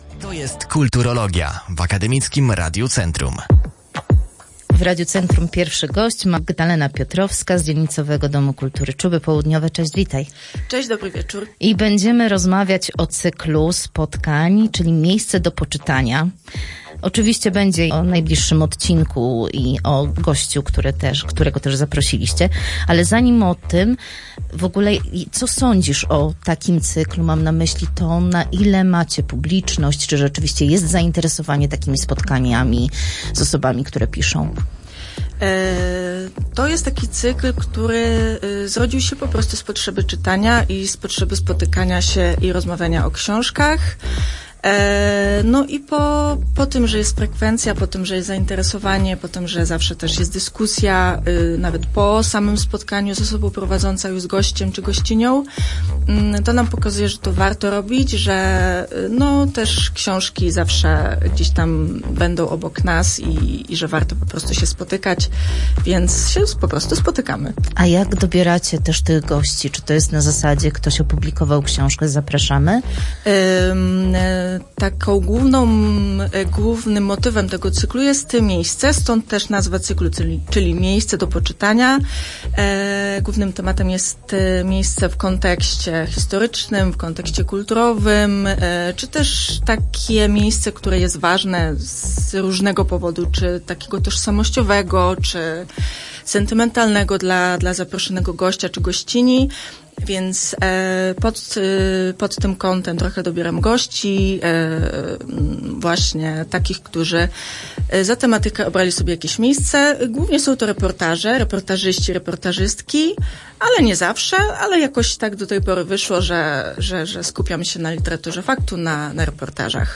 Opublikowano w Aktualności, Kultura, Kulturologia